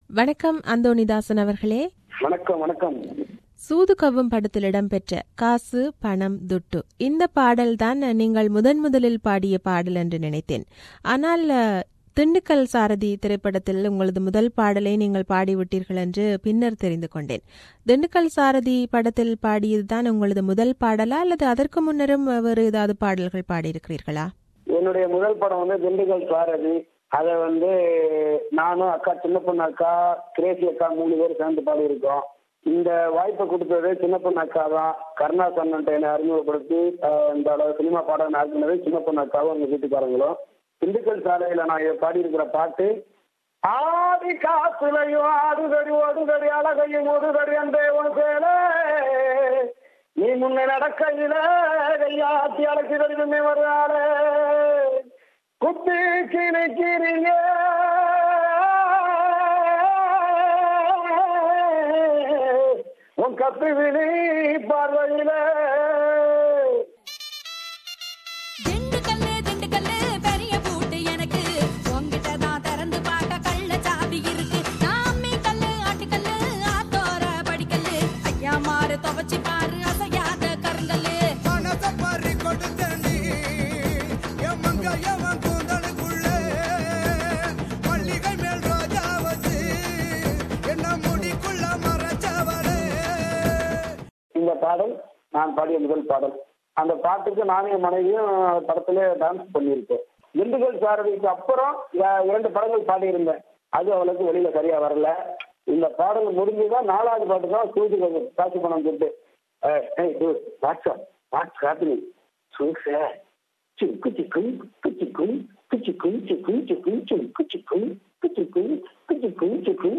This is an interview with him.